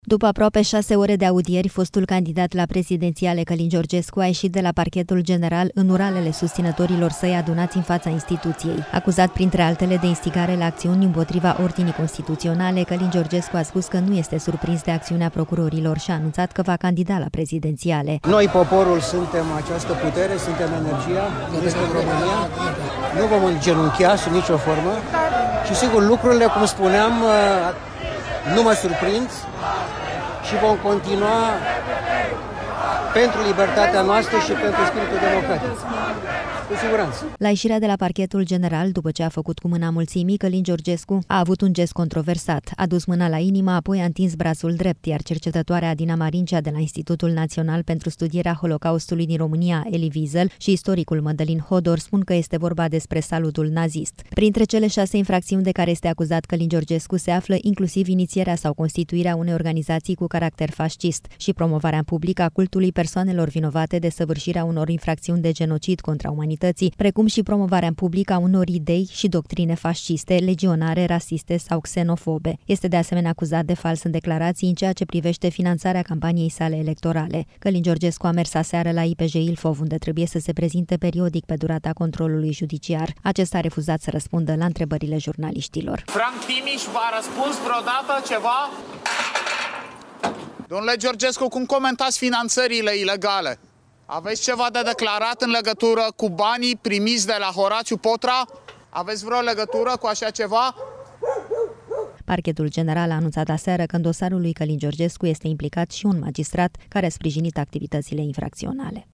După aproape 6 ore de audieri miercuri seară, fostul candidat la prezidențiale Călin Georgescu a ieșit de la Parchetul General, în uralele susținătorilor săi adunați în fața instituției.
Reporter:Veți mai candida, domnule Georgescu?”